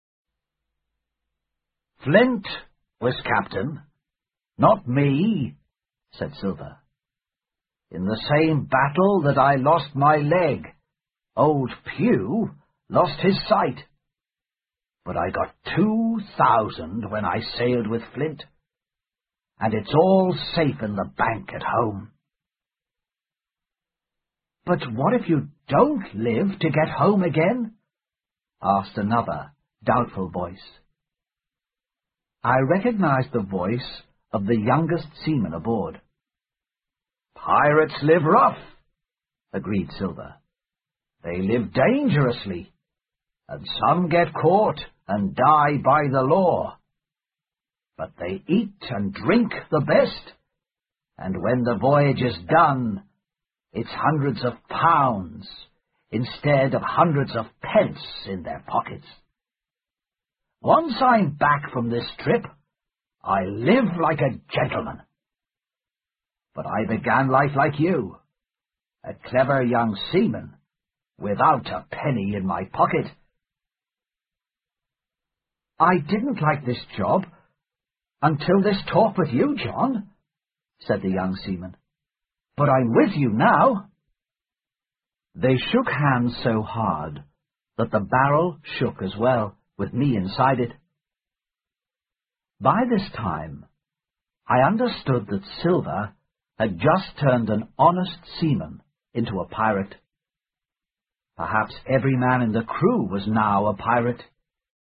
在线英语听力室《金银岛》第七章 苹果桶(4)的听力文件下载,《金银岛》中英双语有声读物附MP3下载